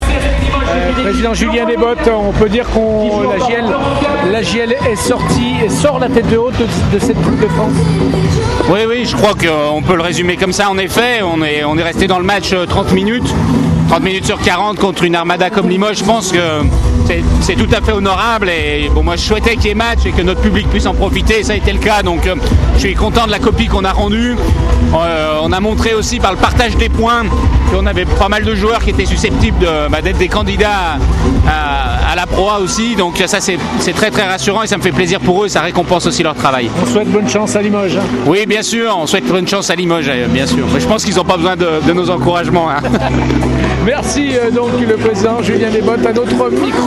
On écoute les réactions d’après-match au micro Radio Scoop